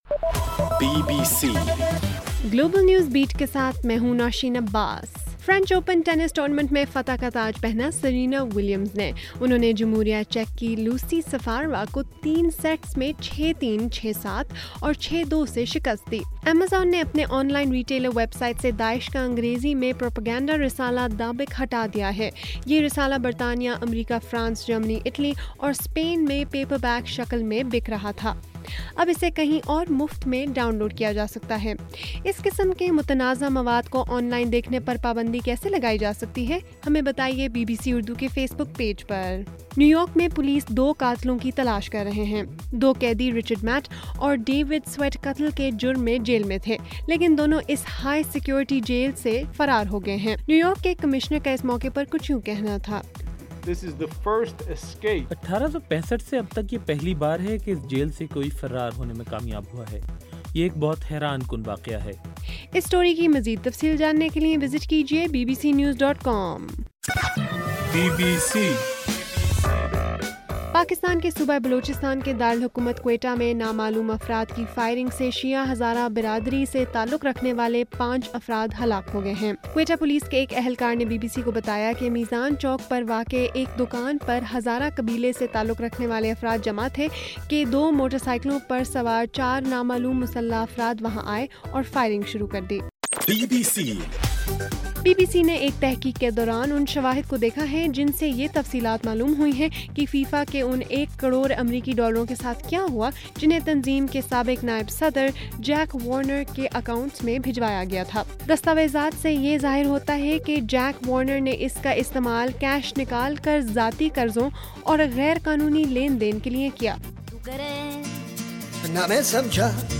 جون 7: رات 10 بجے کا گلوبل نیوز بیٹ بُلیٹن